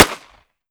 38 SPL Revolver - Gunshot A 005.wav